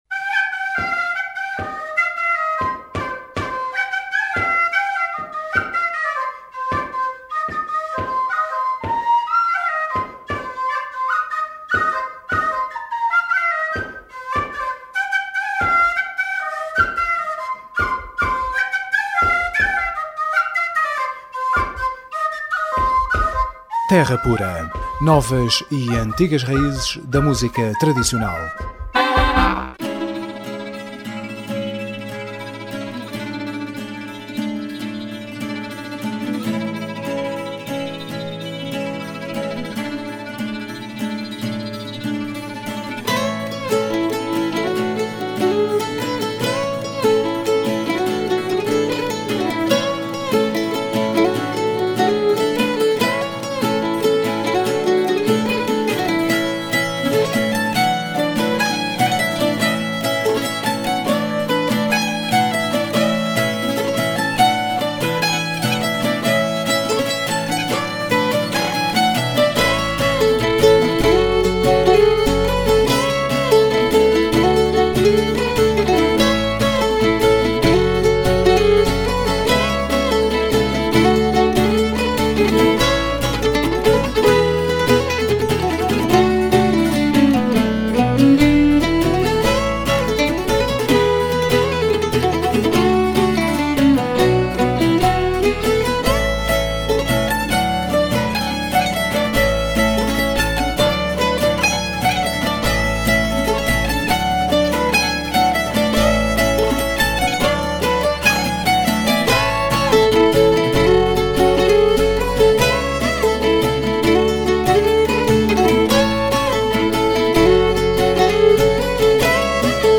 Trio transeuropeu que cria e intrepreta composições originais inspiradas em várias tradições da folk do velho continente, como também adapta viras, muñeiras e jigs e reels do domínio popular com uma abordagem muito própria, onde sobressai o enorme entrosamento e grande técnica com que os três músicos atacam o bandolim, o bouzouki e o violino.